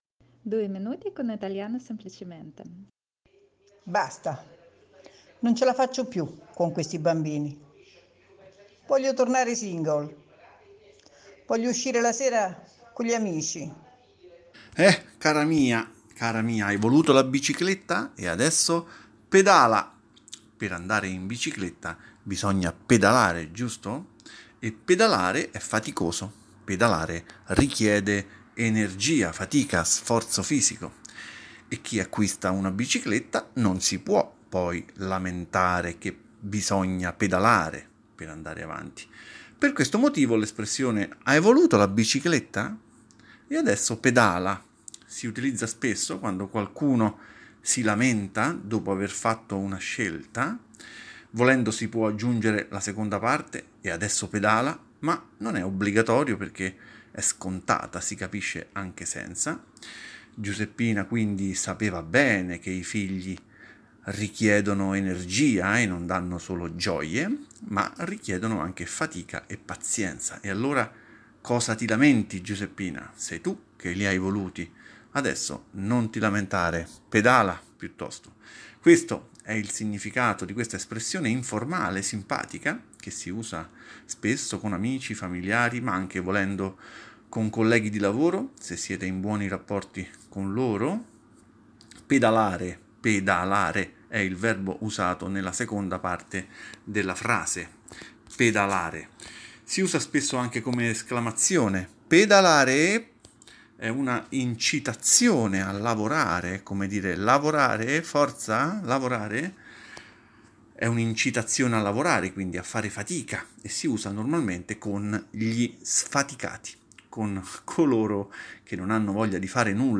L’inizio e/o la fine, o le frasi intermedie di ogni episodio dei “due minuti con Italiano Semplicemente” servono a ripassare le espressioni già viste e sono registrate dai membri dell’associazione.